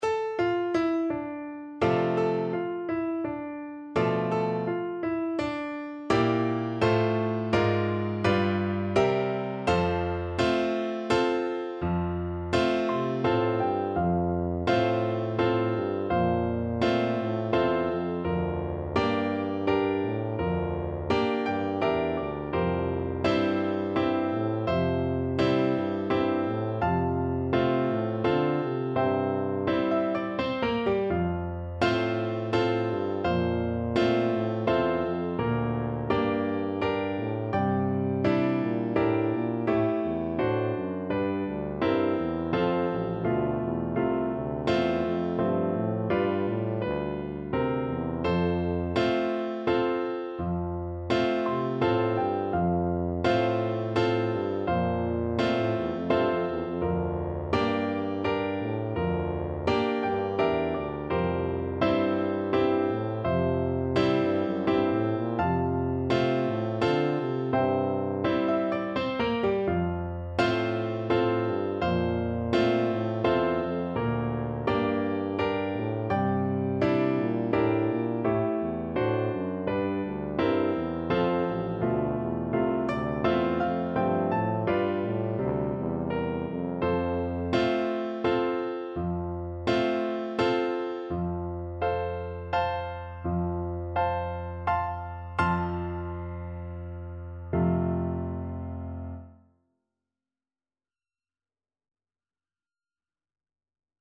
Classical Tchaikovsky, Pyotr Ilyich Theme from Piano Concerto No.1 Tuba version
F major (Sounding Pitch) (View more F major Music for Tuba )
3/4 (View more 3/4 Music)
= 84 Andante non troppe e molto maestoso
Tuba  (View more Intermediate Tuba Music)
Classical (View more Classical Tuba Music)
tchaikovsky_piano_TU.mp3